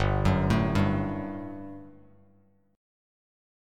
GM7sus2 chord